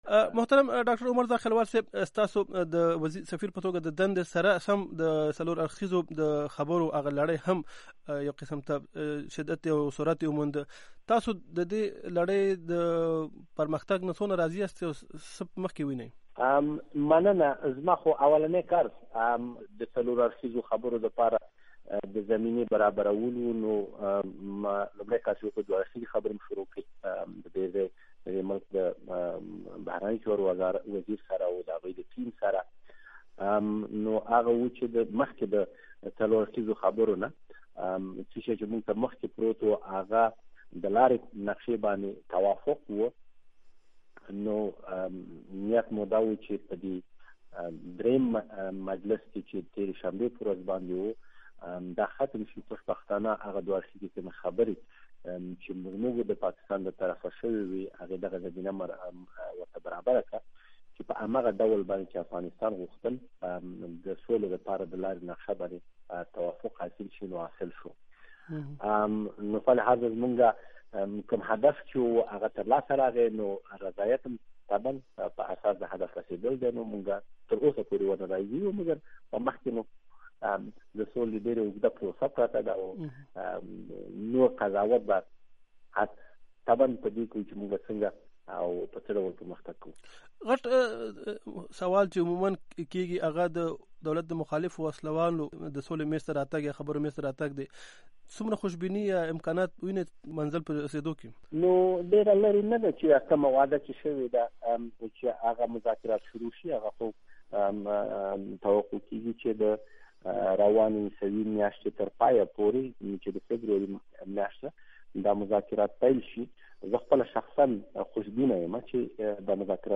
zakhiwal interview